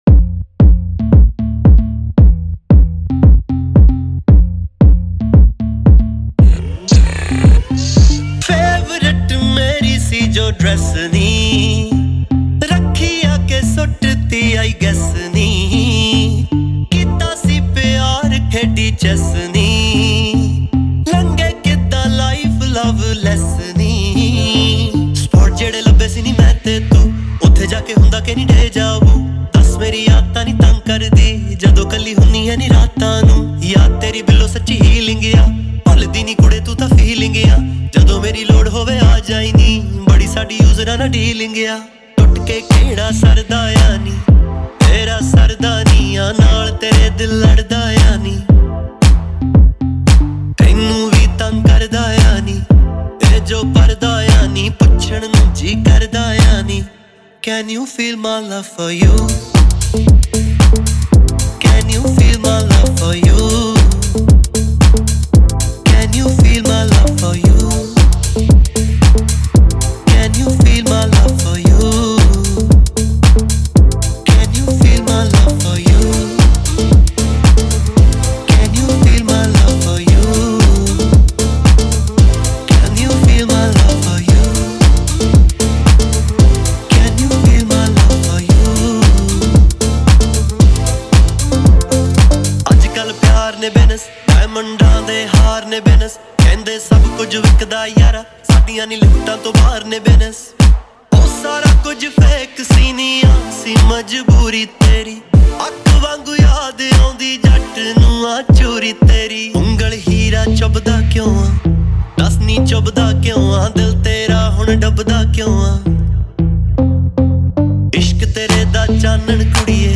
2. Punjabi Songs